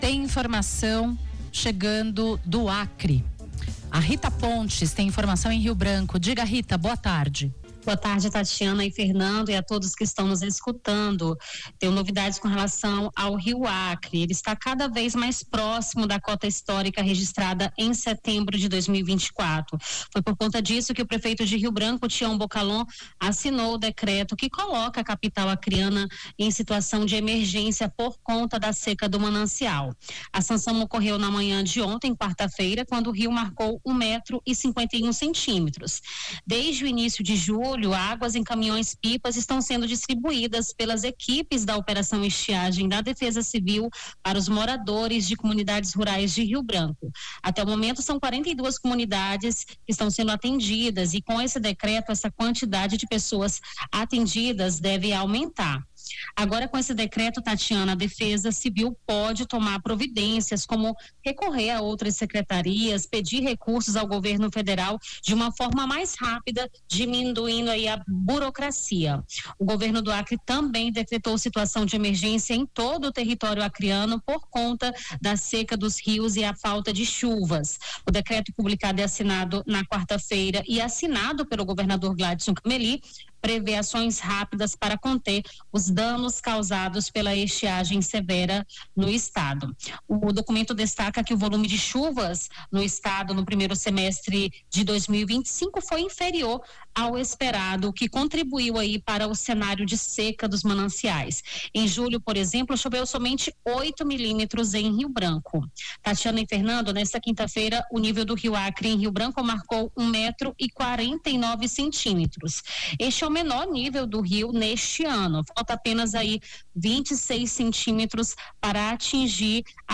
ESTUDIO CBN